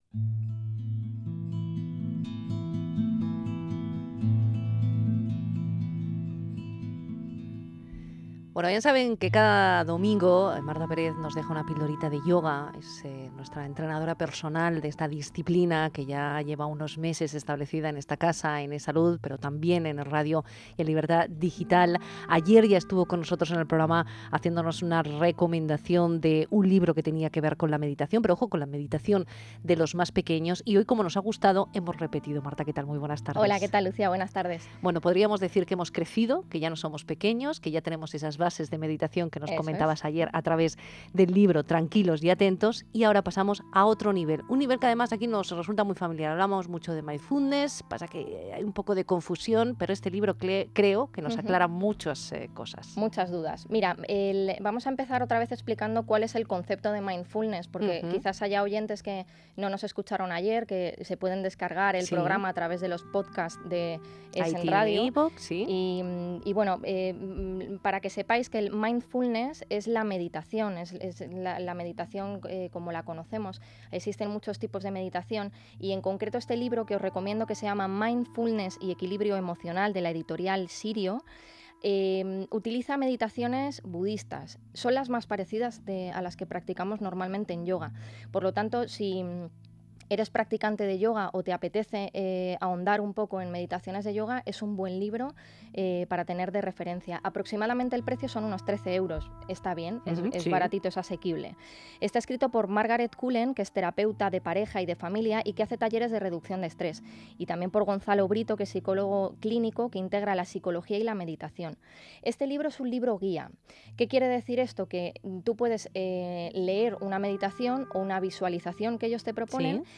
Reseña de "Mindfulness y Equilibrio emocional" en ES Salud (Libertad Digital Radio)